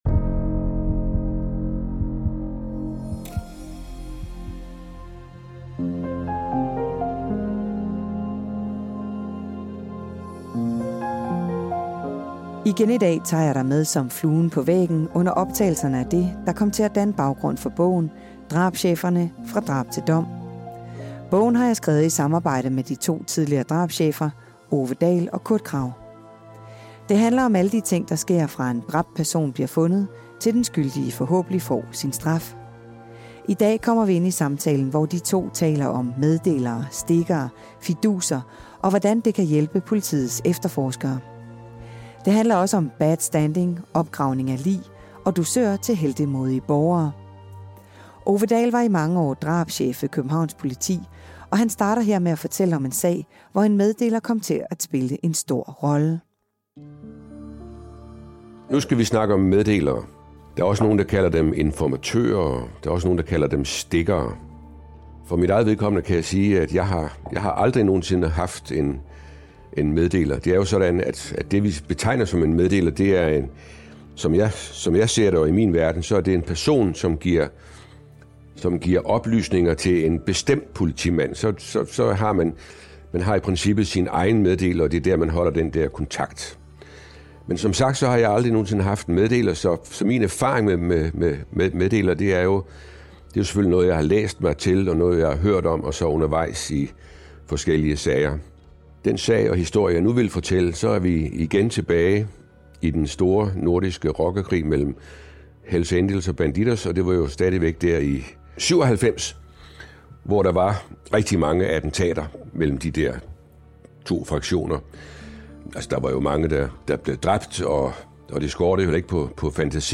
Det handler om meddelere, stikkere, fiduser og hvordan det kan hjælpe politiets efterforskere. Det handler også om bad standing, opgravning af et lig og dusør til heltemodige borgere. Det hele er en bid af de råbånd, der danner baggrund for bogen Drabscheferne - fra drab til dom, som er udkommet på Politikens Forlag.